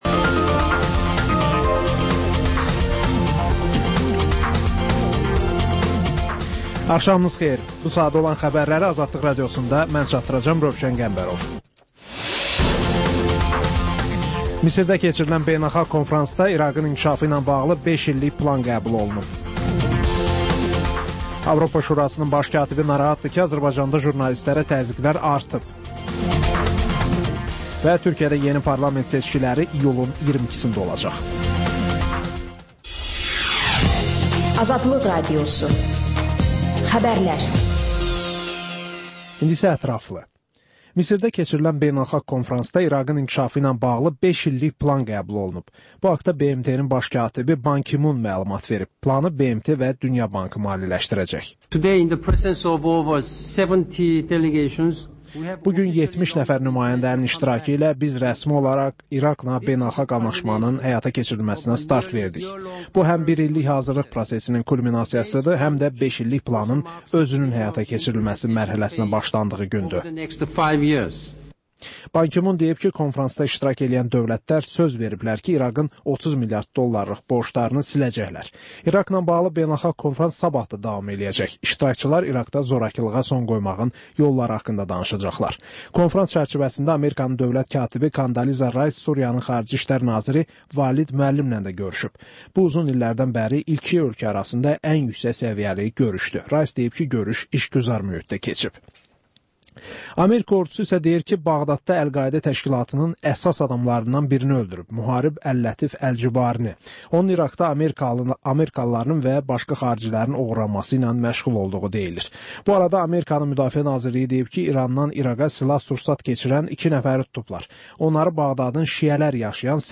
Xəbərlər, müsahibələr, hadisələrin müzakirəsi, təhlillər, sonda TANINMIŞLAR verilişi: Ölkənin tanınmış simalarıyla söhbət